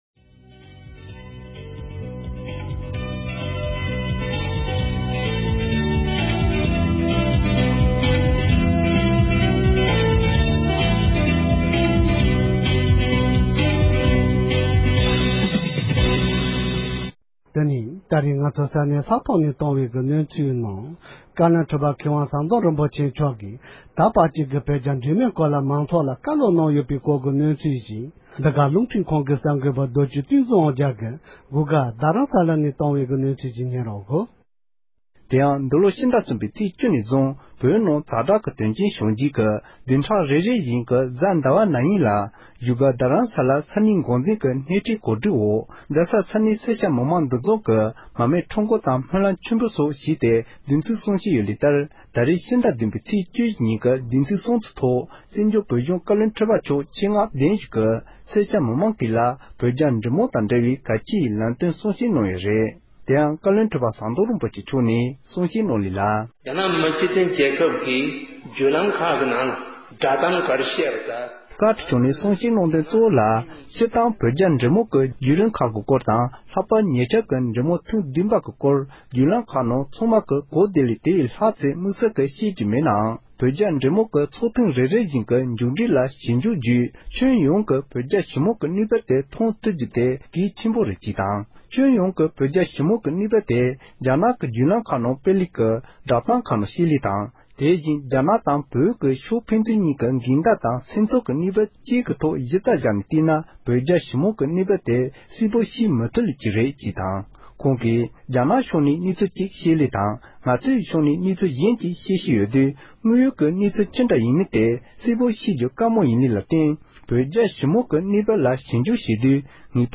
བཀའ་བློན་ཁྲི་པ་མཆོག་གི་བཀའ་སློབ།